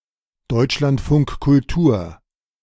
Deutschlandfunk Kultur (German: [ˈdɔʏtʃlantˌfʊŋk kʊlˈtuːɐ̯]